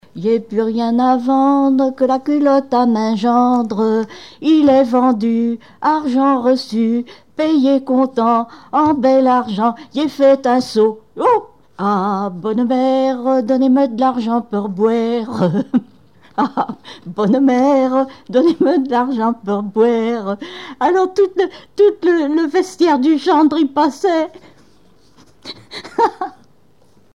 Genre énumérative
Témoignages et chansons
Pièce musicale inédite